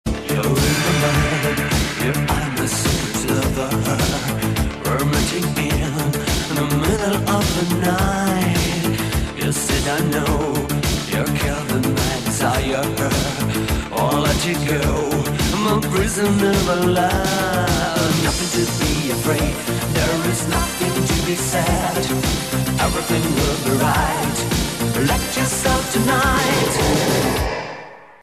• Качество: 128, Stereo
мужской вокал
женский вокал
Italo Disco
dance
EDM
спокойные
красивая мелодия
дискотека 80-х